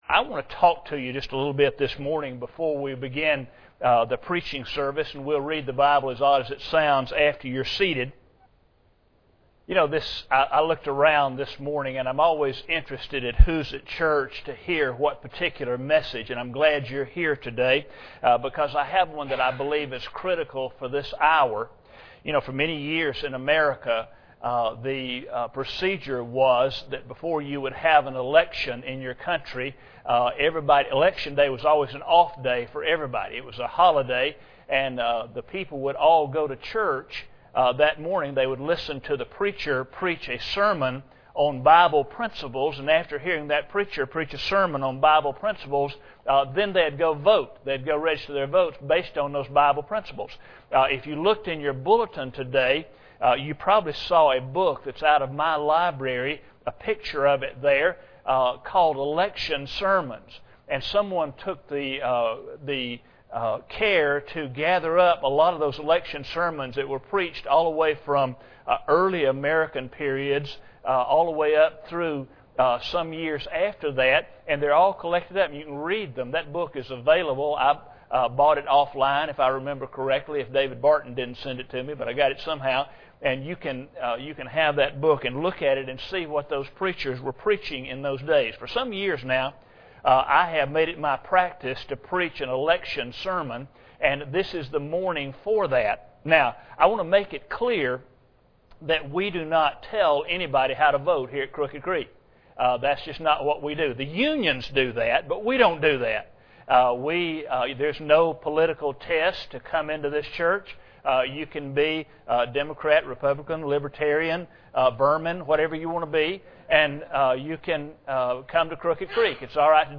Luke 12:48 Service Type: Sunday Morning Bible Text
ElectionSermon2012.mp3